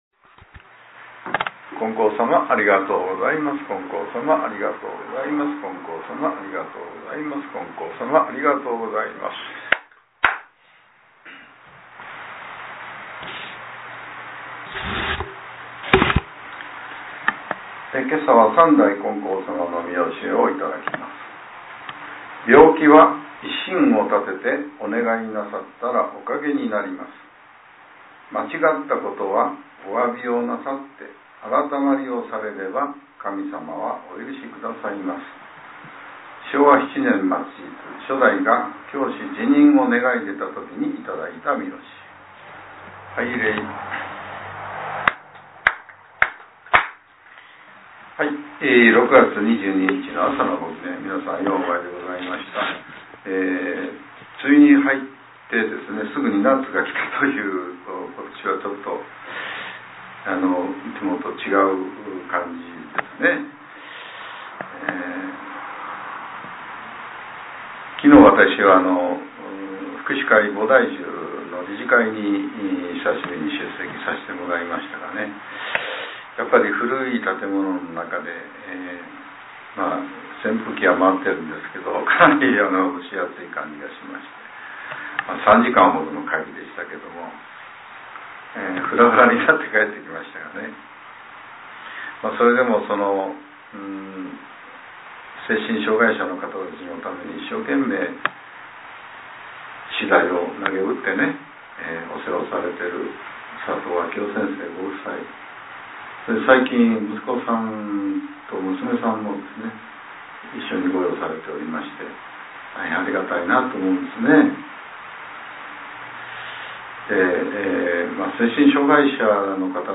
令和７年６月２２日（朝）のお話が、音声ブログとして更新されています。